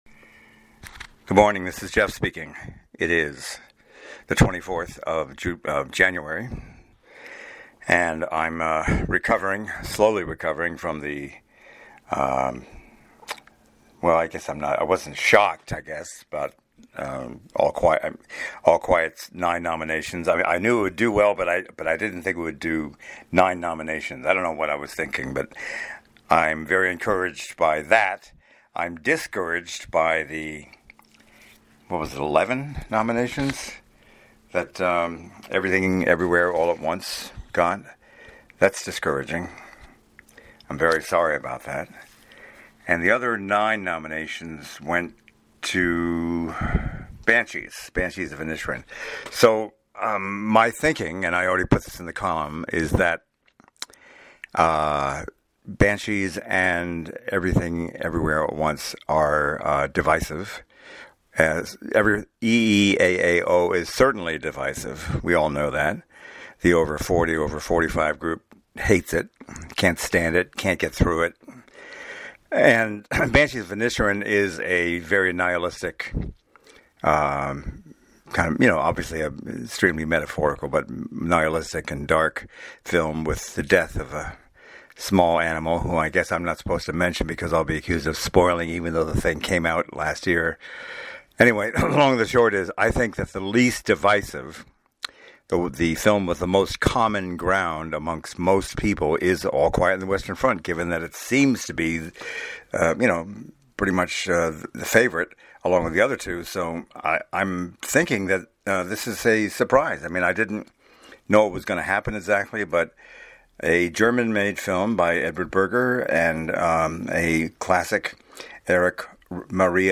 I’ve just verbalized some reactions to this morning’s Oscar nominations.